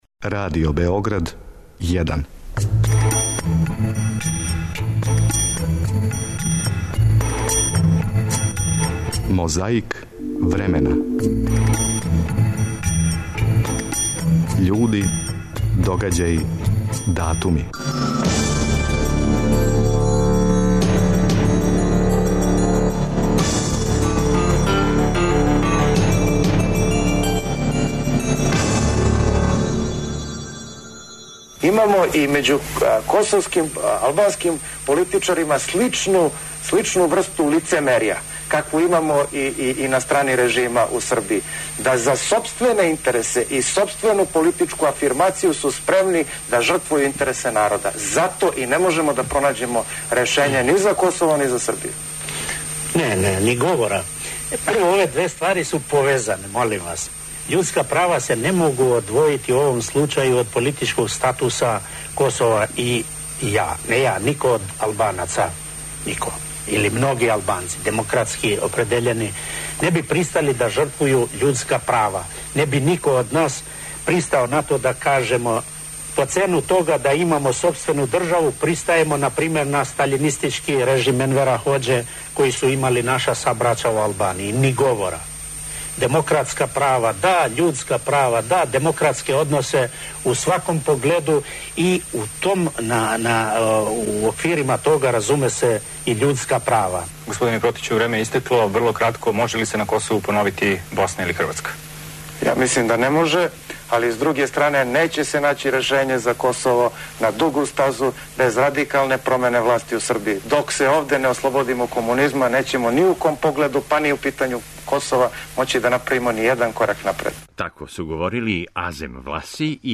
У Београду је 4. априла 1991. године одржана конференција за новинаре поводом завршетка другог састанка шесторице републичких председника.
Подсећа на прошлост (културну, историјску, политичку, спортску и сваку другу) уз помоћ материјала из Тонског архива, Документације и библиотеке Радио Београда. Свака коцкица Мозаика је један датум из прошлости.